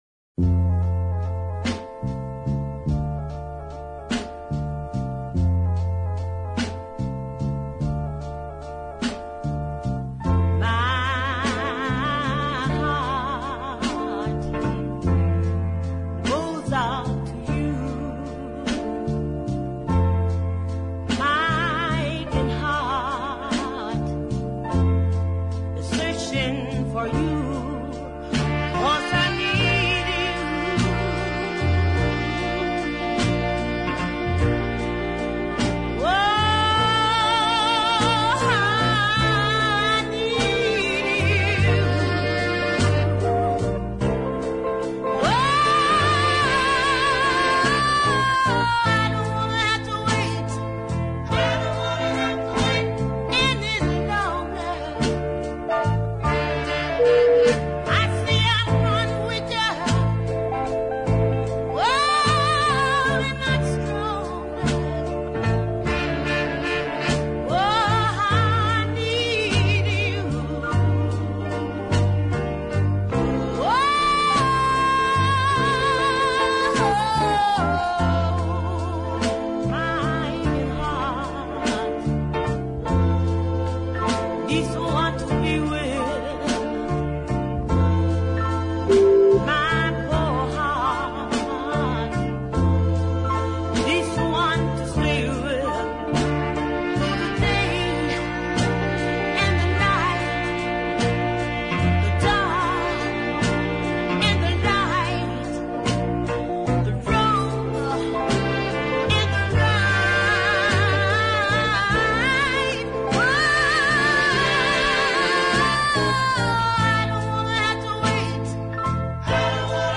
typically effective plaintive ballad